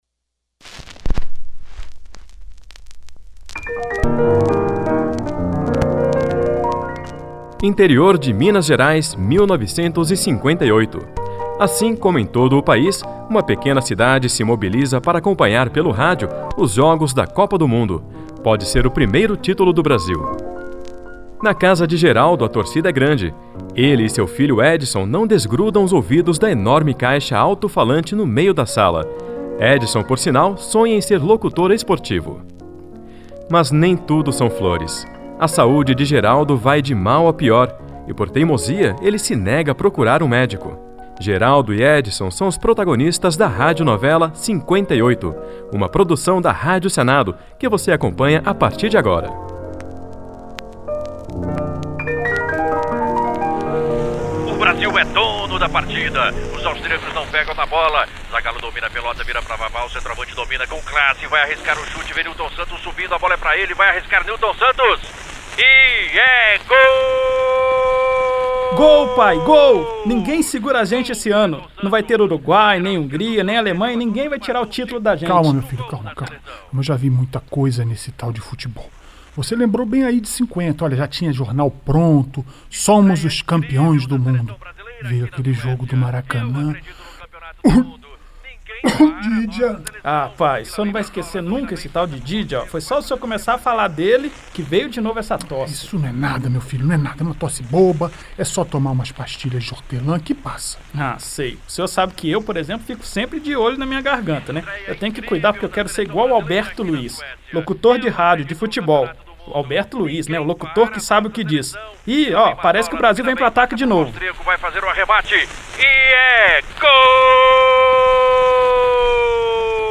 Radionovela da Rádio Senado relembra a campanha brasileira na Copa de 58.